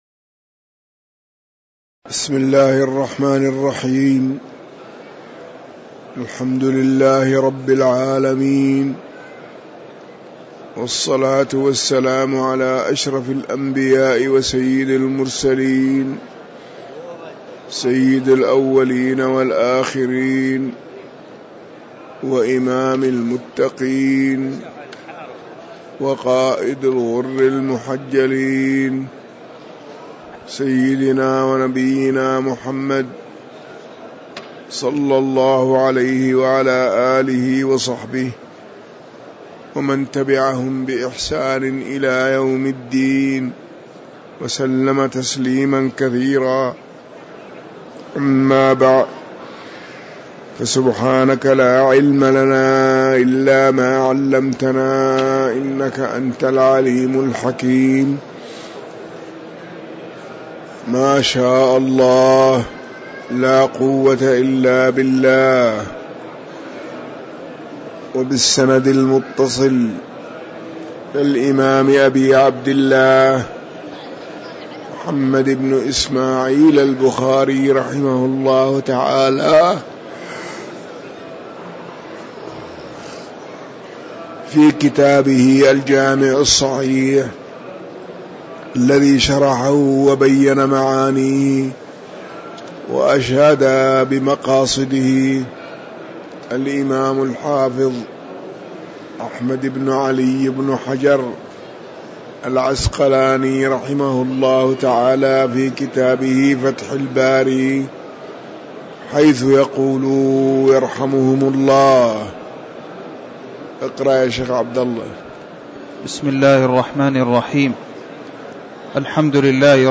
تاريخ النشر ١٦ جمادى الآخرة ١٤٤١ هـ المكان: المسجد النبوي الشيخ